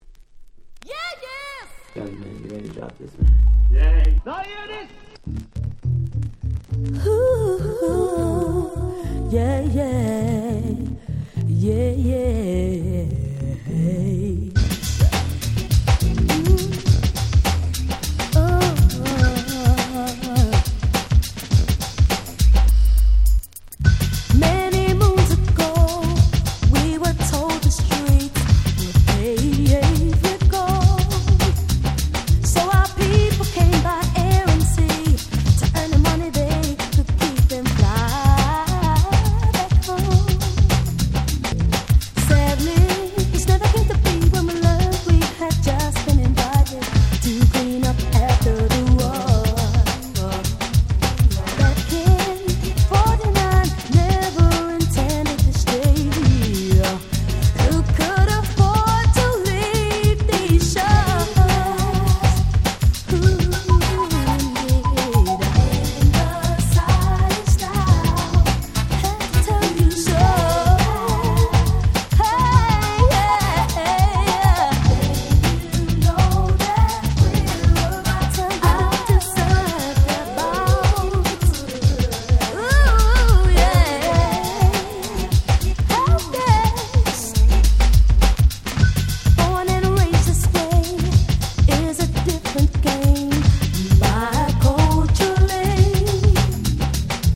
90' Nice UK R&B / Ground Beat !!